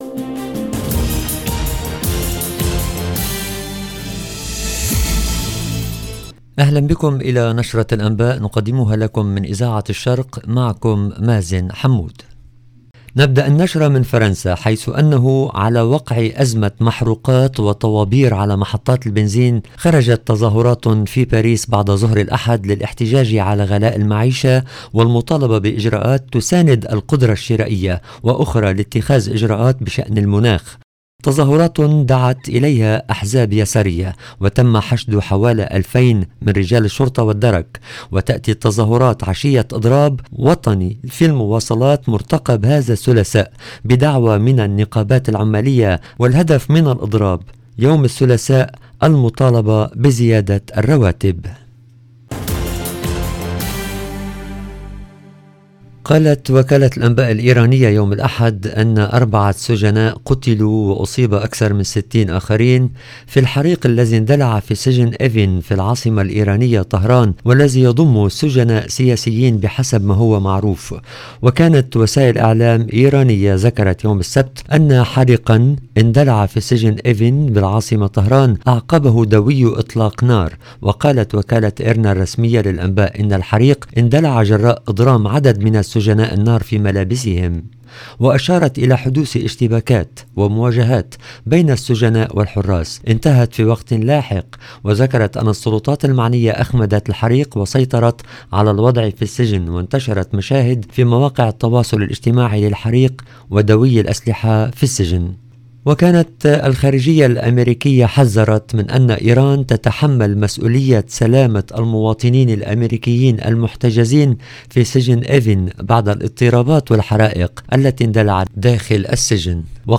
LE JOURNAL DU SOIR EN LANGUE ARABE DU 16/10/2022